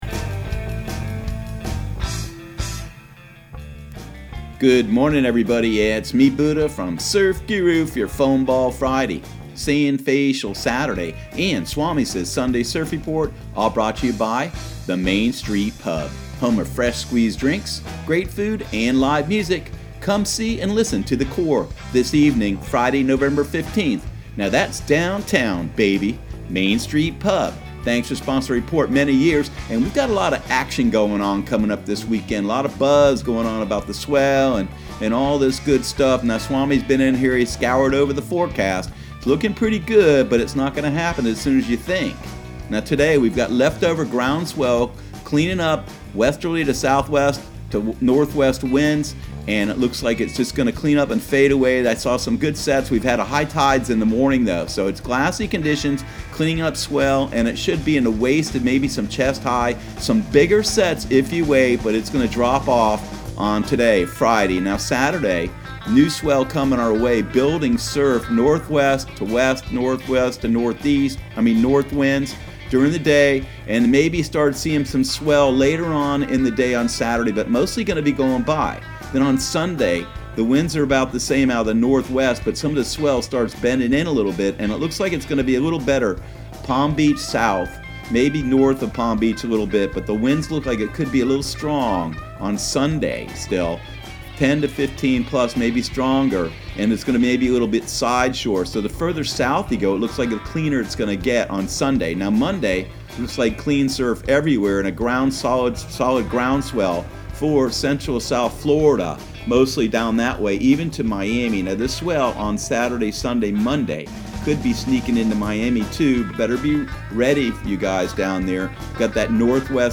Surf Guru Surf Report and Forecast 11/15/2019 Audio surf report and surf forecast on November 15 for Central Florida and the Southeast.